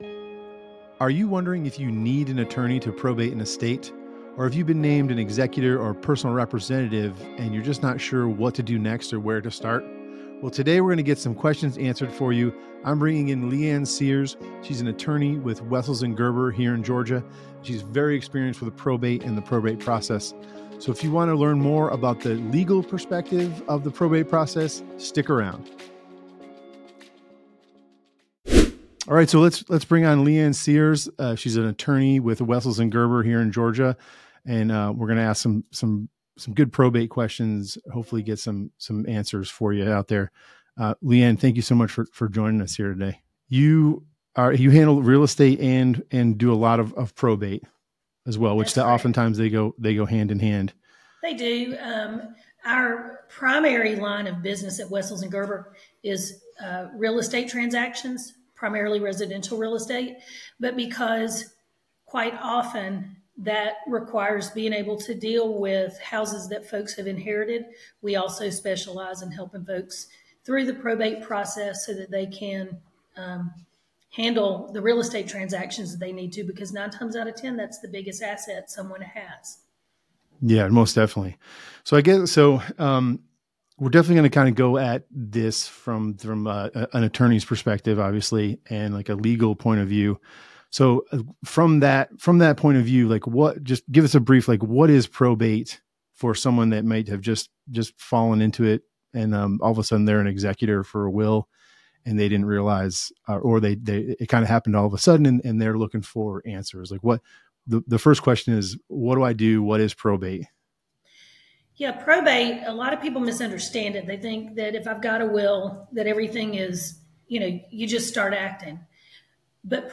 Probate Property Advisors Conversation with a Probate Attorney Jan 17 2024 | 00:30:51 1x 00:00 / 00:30:51 Subscribe Share Spotify RSS Feed Share Link Embed